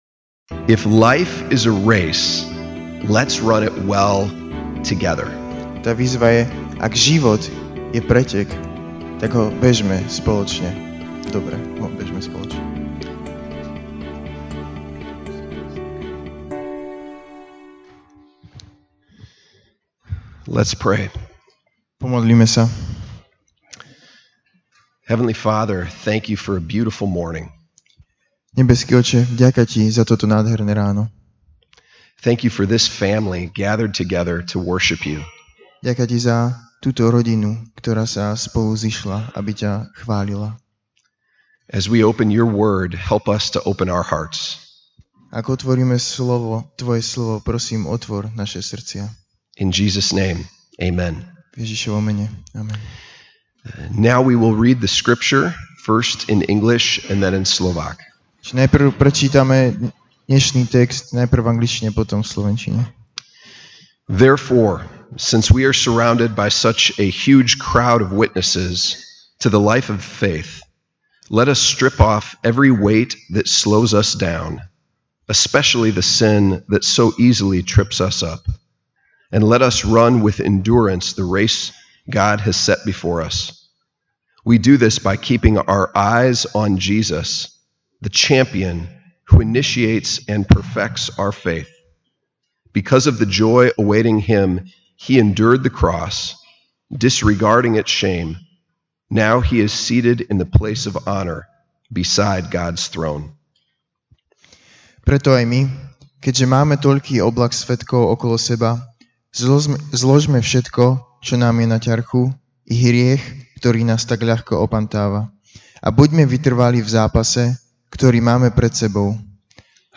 Ranná kázeň: Vytrvaj na ceste (Židom 12, 1-2)Preto aj my, keďže máme toľký oblak svedkov okolo seba, zložme všetko, čo nám je na ťarchu, i hriech, ktorý nás tak ľahko opantáva, a buďme vytrvalí v zápase, ktorý máme pred sebou.